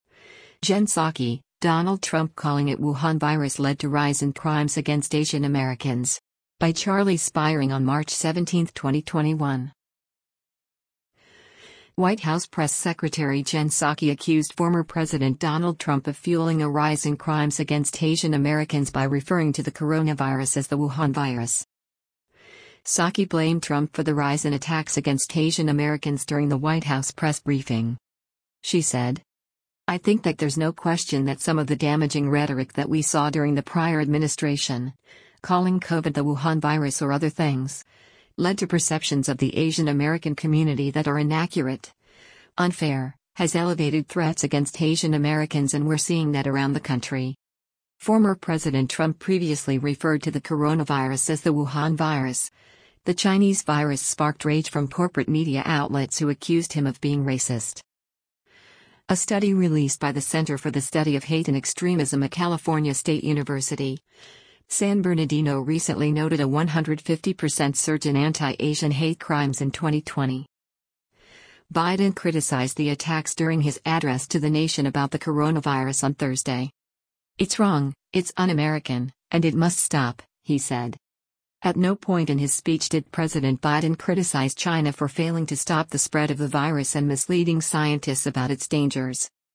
Psaki blamed Trump for the rise in attacks against Asian-Americans during the White House press briefing.